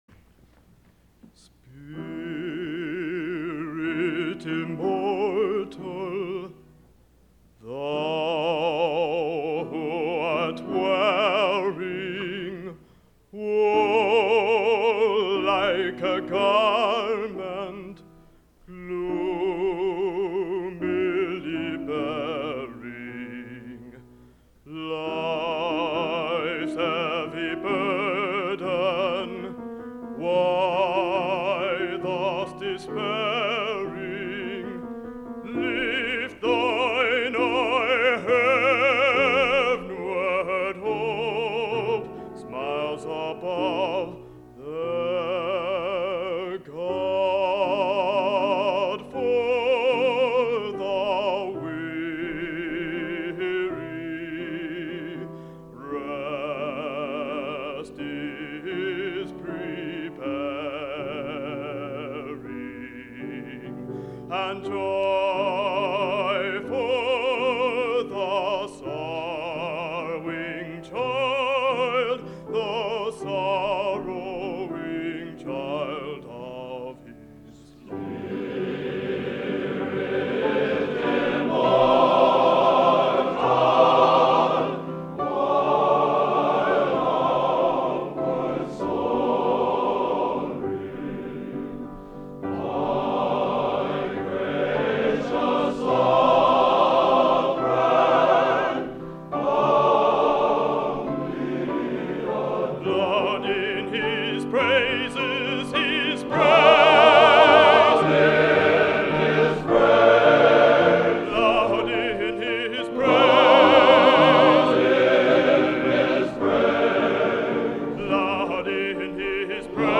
Genre: | Type: End of Season |Featuring Hall of Famer